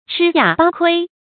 吃哑巴亏 chī yǎ bā kuī
吃哑巴亏发音